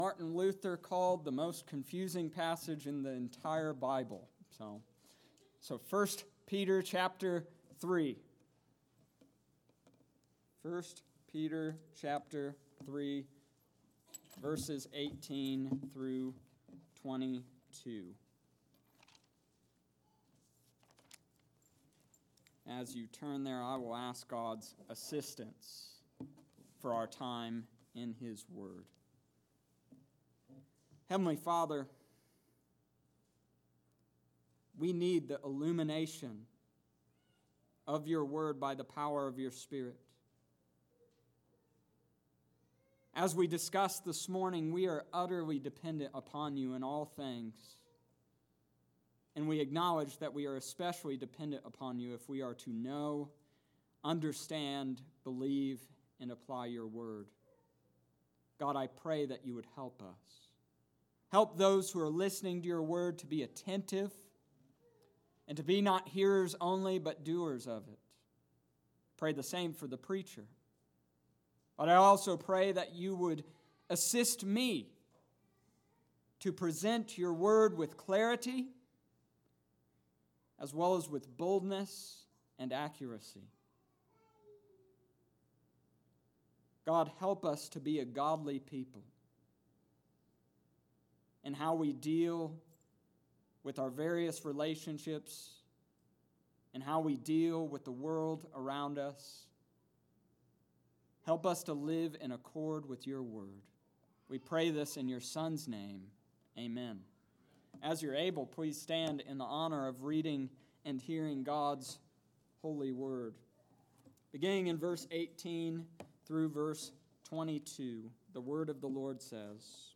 The Epitome and Reason for Godliness 1 Peter chapter 3 verses 18-22 Sunday evening service Aug.11th 2019